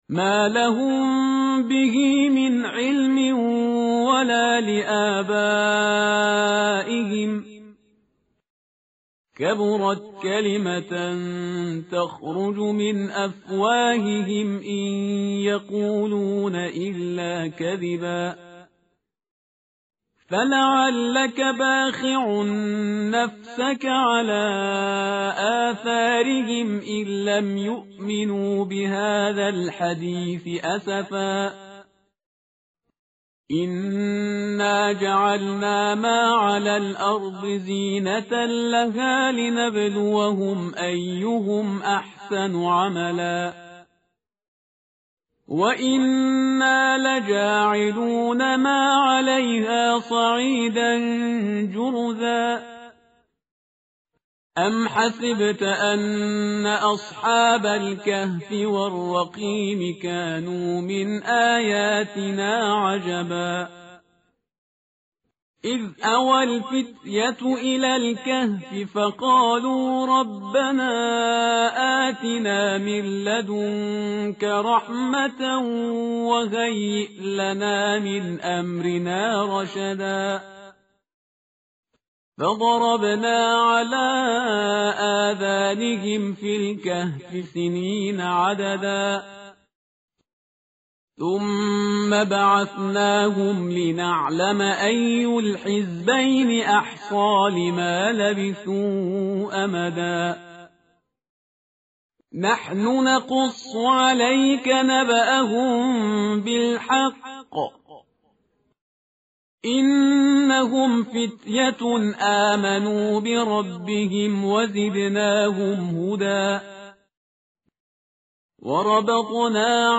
tartil_parhizgar_page_294.mp3